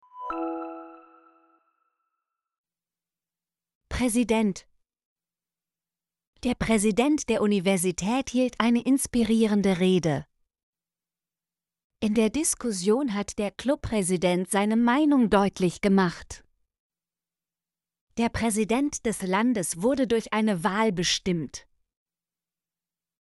präsident - Example Sentences & Pronunciation, German Frequency List